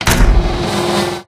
Door5.ogg